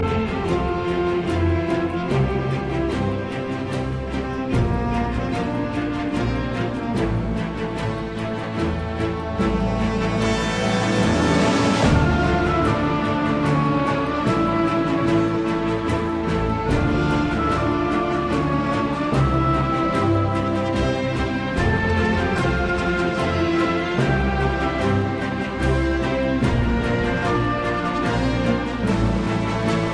key: d minor